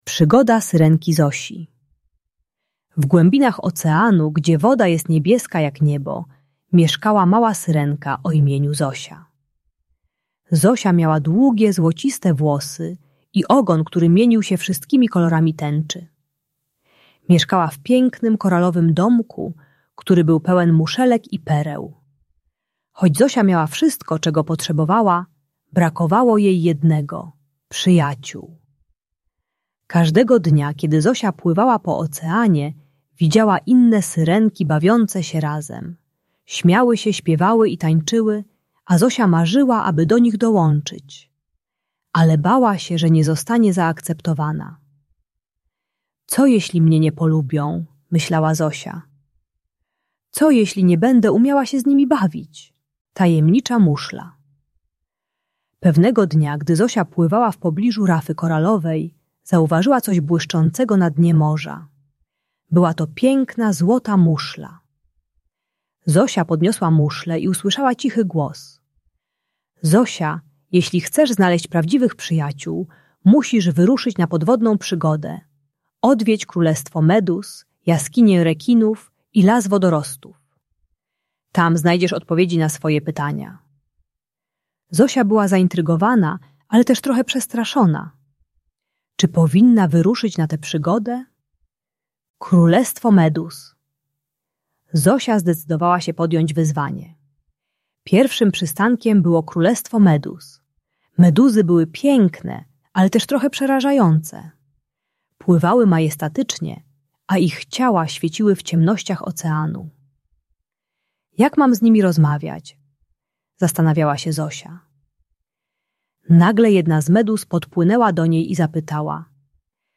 Przygoda Syrenki Zosi - Lęk wycofanie | Audiobajka